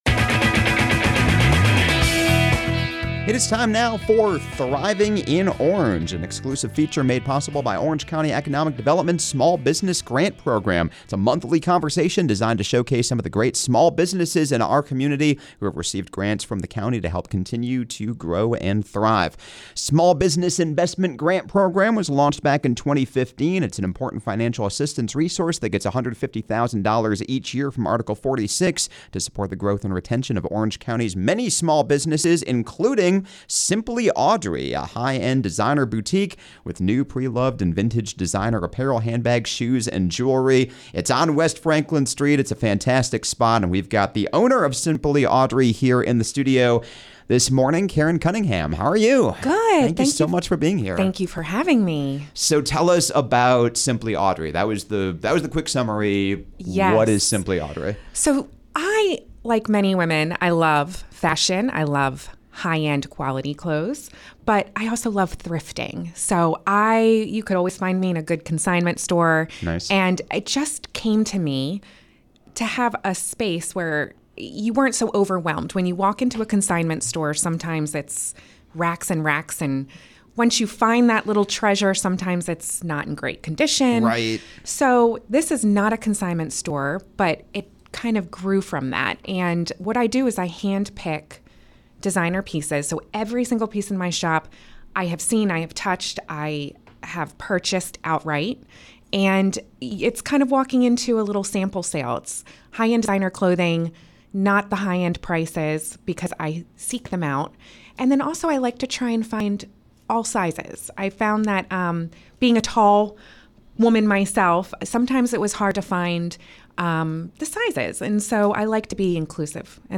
Welcome to “Thriving in Orange!” A monthly segment presented by Orange County Economic Development, “Thriving in Orange” features conversations with local business owners about what it’s like to live and work in Orange County, especially in light of the county’s small business grant program which launched in 2015 and has helped small businesses and small business owners with well over $100,000 in grants each year!
You can find more conversations like this in the “Thriving in Orange” archive on Chapelboro, and each month in a special segment airing on 97.9 The Hill!